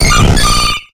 infinitefusion-e18/Audio/SE/Cries/BLISSEY.ogg at a50151c4af7b086115dea36392b4bdbb65a07231